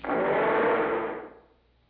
Let's face it: Scarface's roar is not as impressive as Grumpy's. Listen: Scarface's roar
roar.wav